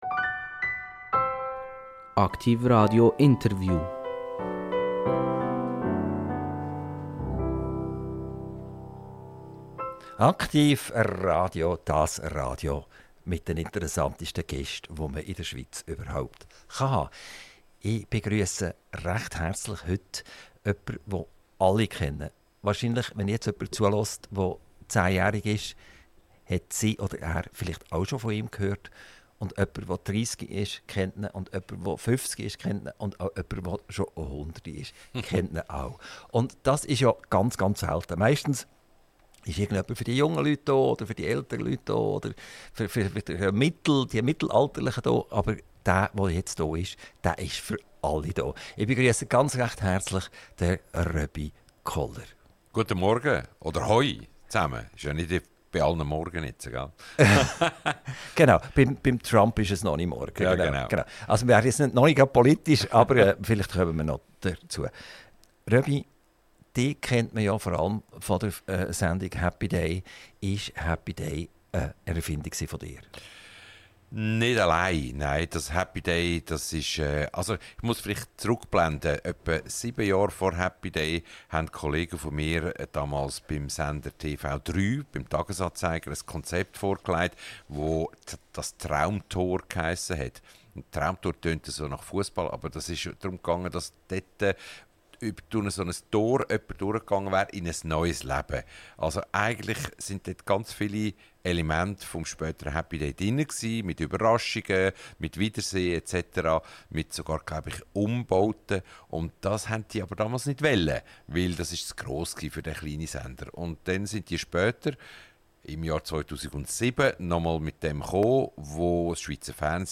INTERVIEW - Röbi Koller - 18.09.2025 ~ AKTIV RADIO Podcast